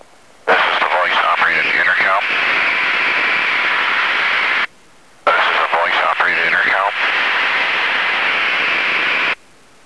In flight audio from a voice operated intercom.
The recorded audio is audio from the intercom output.
The recorded audio is what is heard through the headphone's ear cup speaker.
•  With a voice operated intercom, wind and engine noise is heard when the headset's microphone audio path is open.